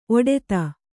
♪ oḍeta